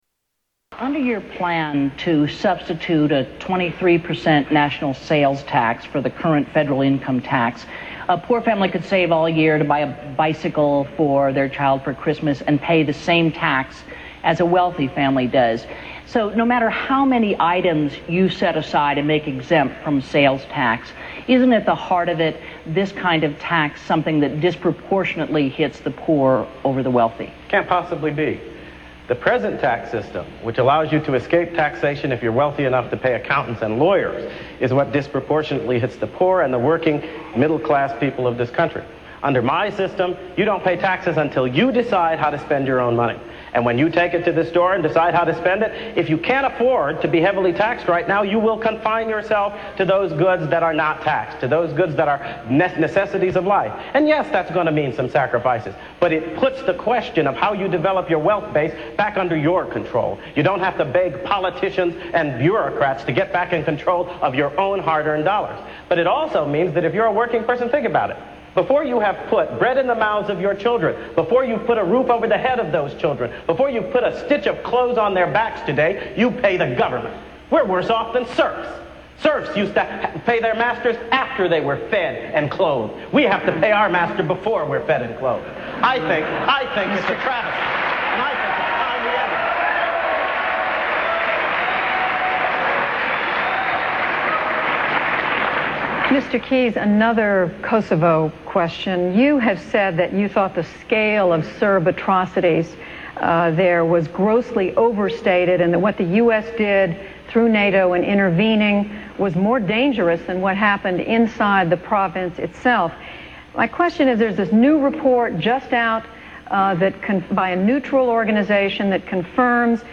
2000 GOP Debate